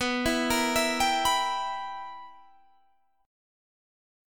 BM7sus4#5 Chord